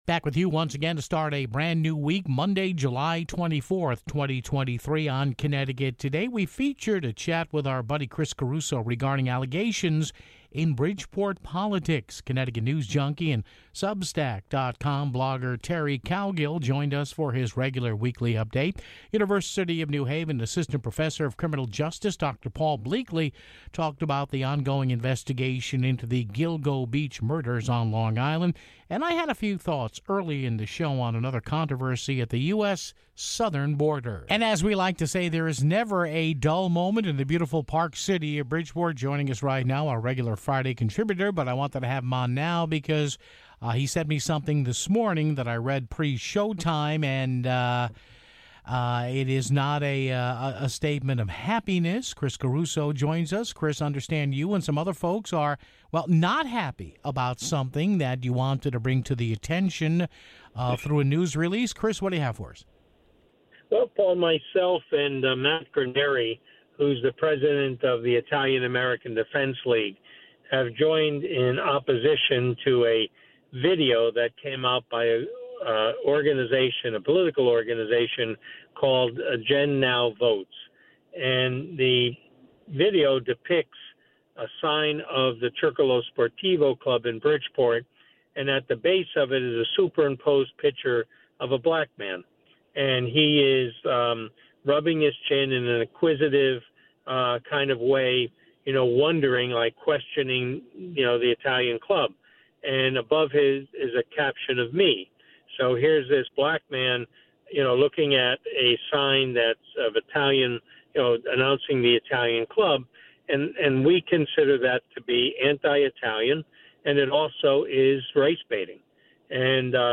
featured a chat